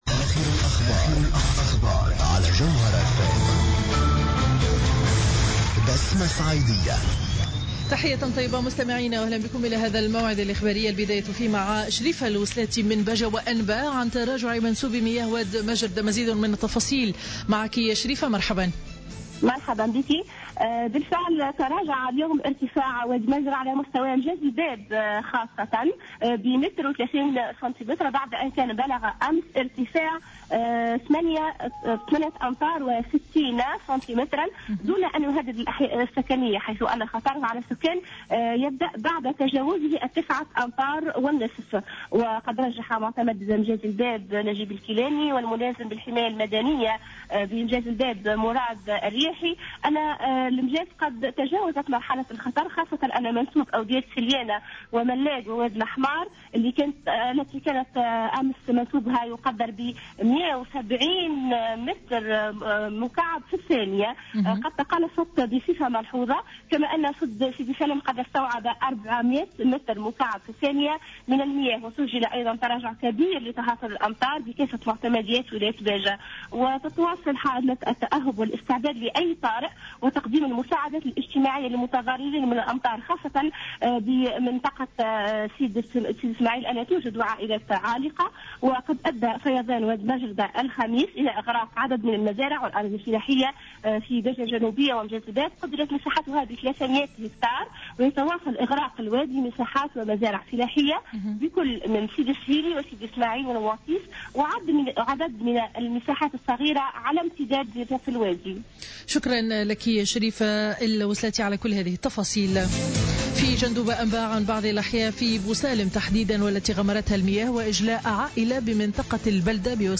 نشرة أخبار منتصف النهار ليوم الجمعة 27 فيفري 2015